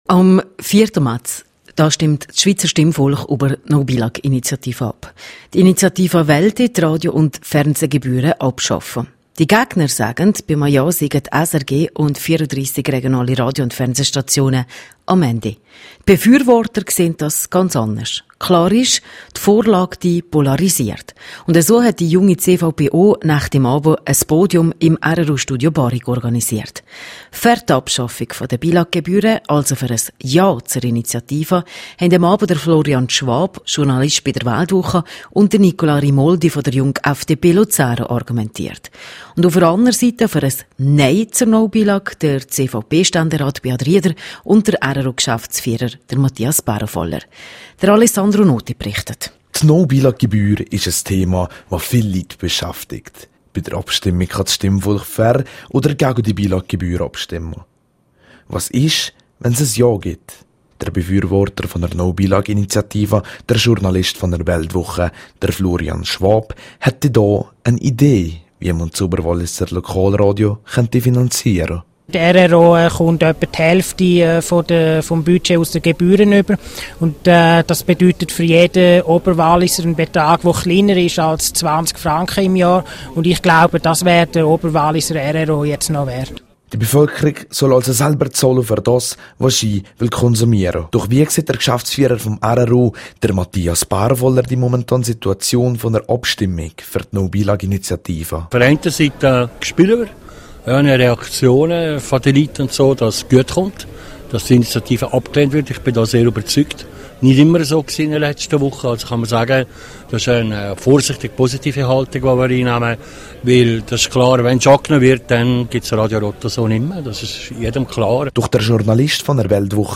Am Donnerstagabend lud die Junge CVP Oberwallis zu einer Podiumsdiskussion zur No-Billag-Initiative ins rro-Studio Barrique in Eyholz ein.